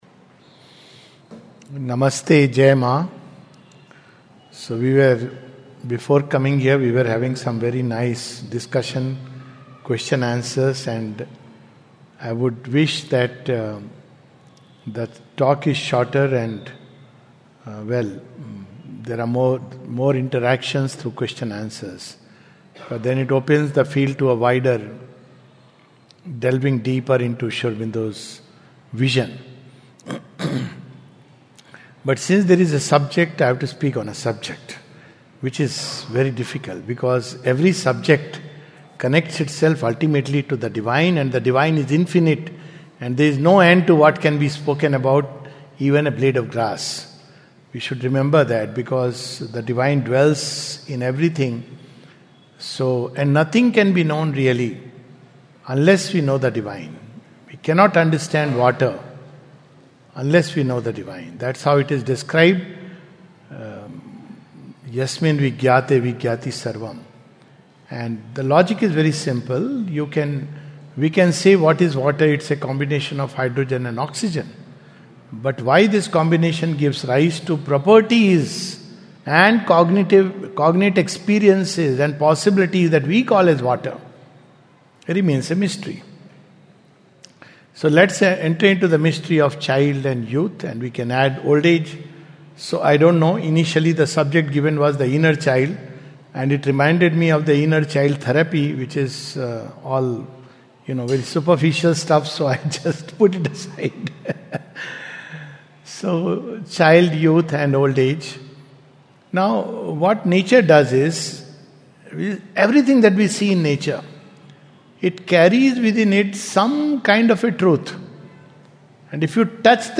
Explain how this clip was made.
A talk at the JP Nagar Sri Aurobindo Society Center, Bangalore. The talk is followed by questions and answers about the Path and the Journey.